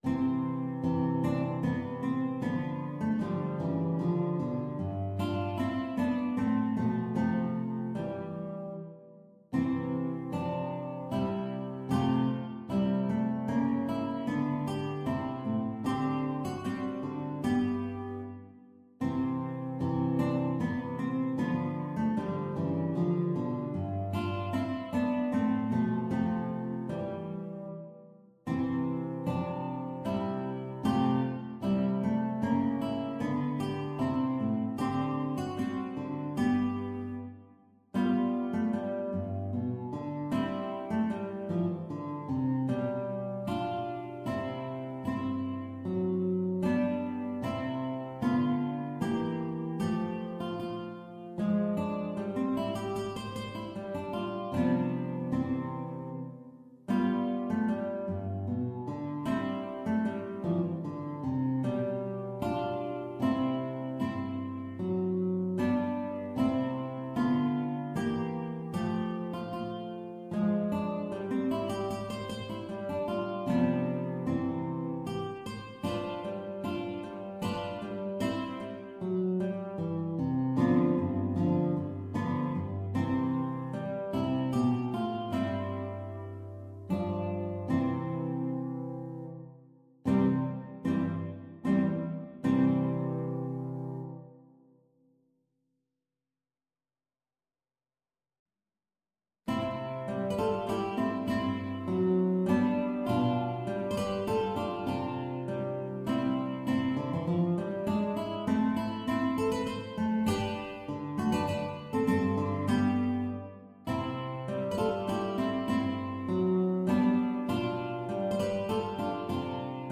Instrument: Guitar
Style: Classical